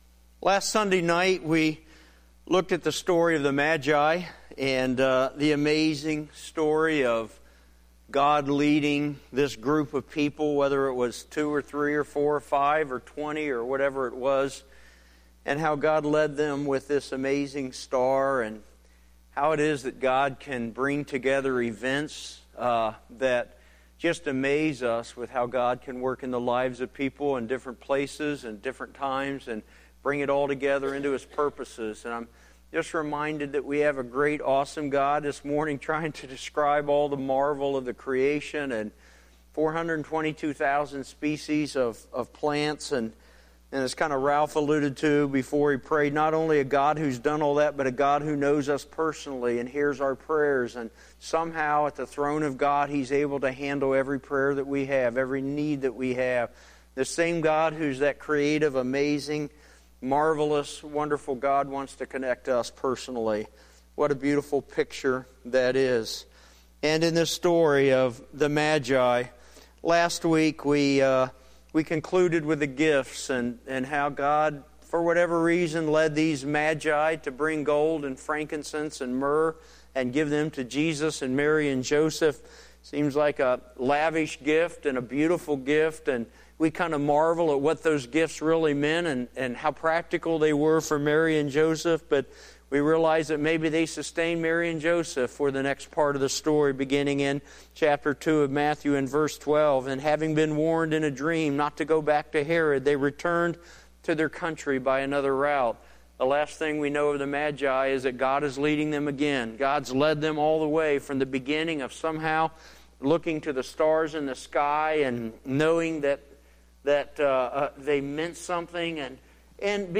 1-19-14-pm-Sermon.mp3